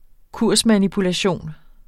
Udtale [ ˈkuɐ̯ˀsmanipulaˌɕoˀn ]